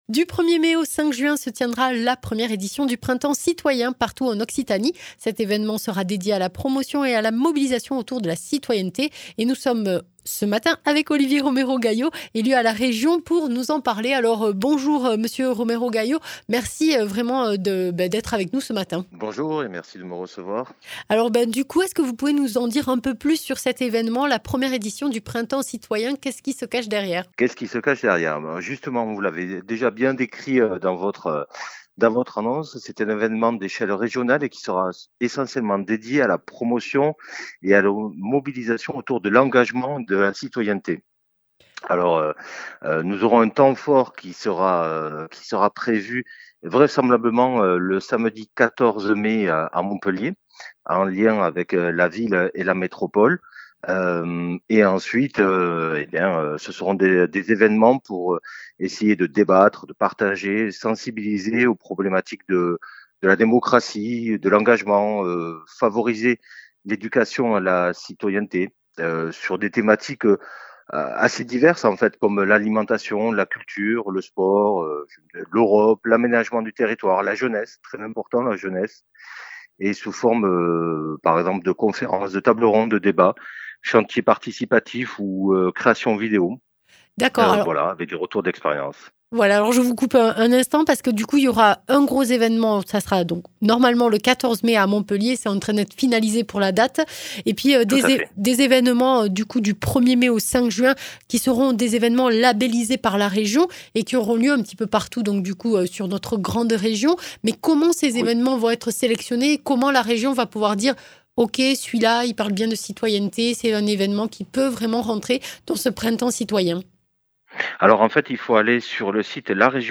Interviews
Invité(s) : Olivier Romero-Gayo, élu de la Haute-Garonne à la région Occitanie